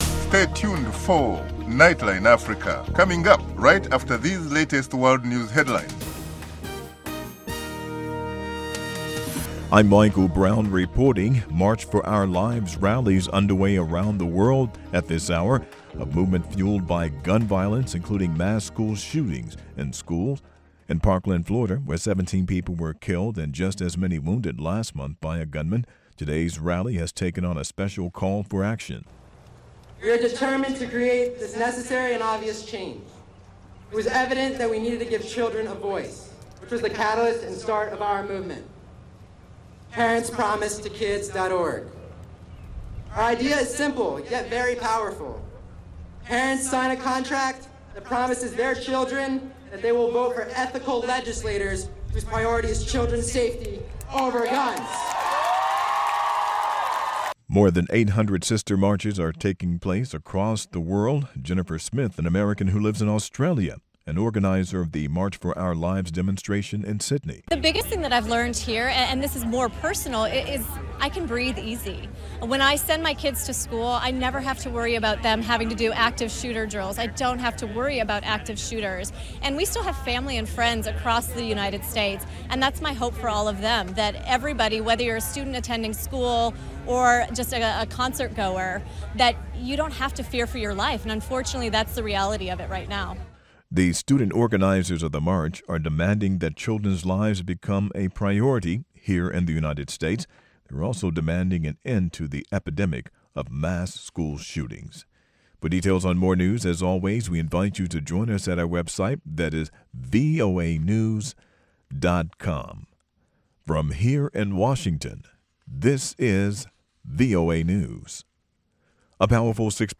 Join our host and correspondents from Washington and across Africa as they bring you in-depth interviews, news reports, analysis and features on this 60-minute news magazine show.